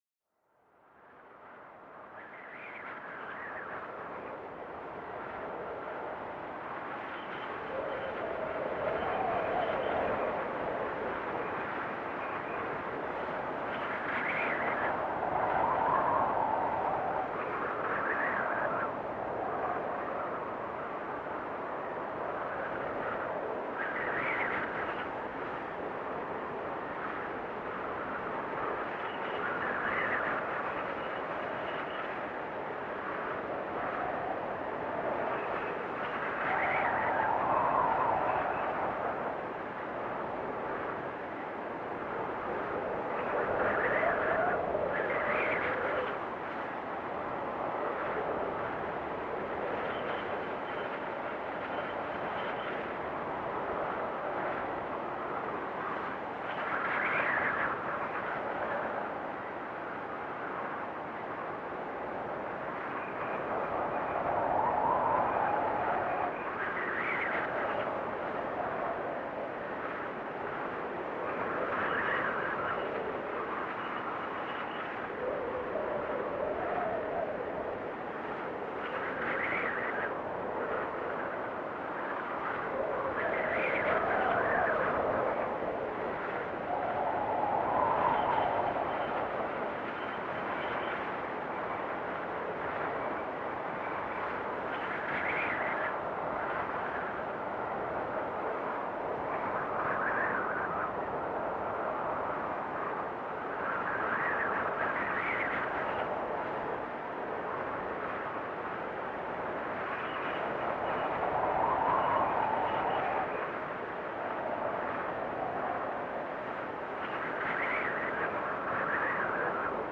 Sumérgete en el corazón de un blizzard donde el viento se convierte en un rugido poderoso, esculpiendo la nieve en formas efímeras. Experimenta el impacto hipnotizante del viento helado, capaz de transformar paisajes en obras maestras invernales fugaces.
Una invitación a dejarse mecer por los sonidos poderosos y tranquilizadores de la naturaleza para encontrar la serenidad y el descanso.